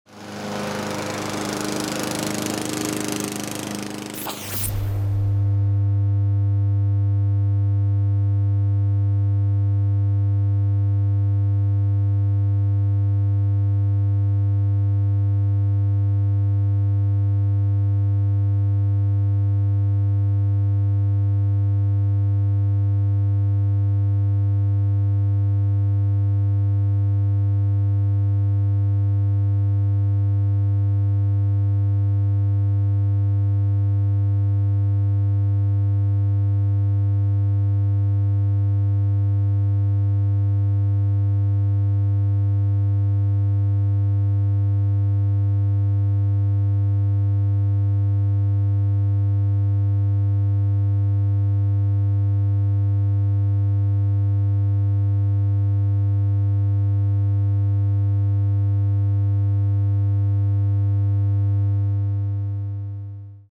Hier gibts den Ton (inklusive Rasenmäher-Geräusch zum richtigen einpendeln auf ca. 80 dB) zum Herunterladen, gute Fahrt! 👇
100 Hertz mit Rasenmäher als Vergleich
ton-hilft-gegen-reisekrankheit-108.mp3